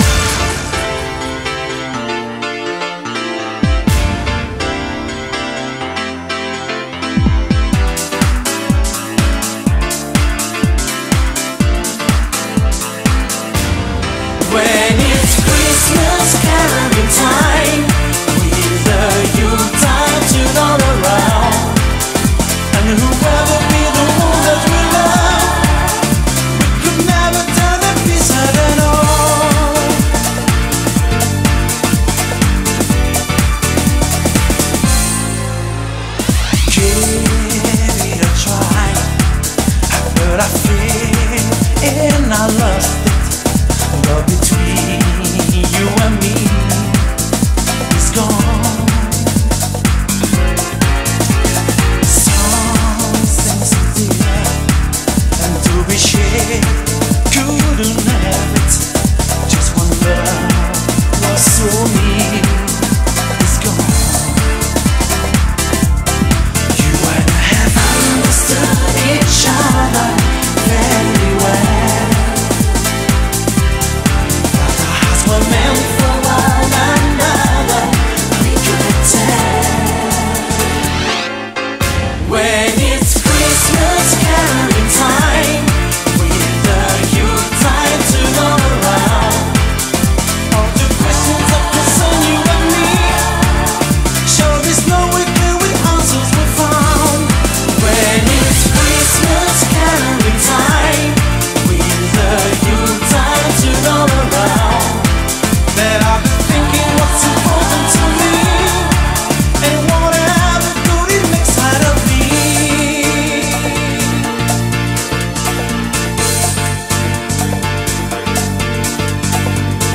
BPM124
MP3 QualityMusic Cut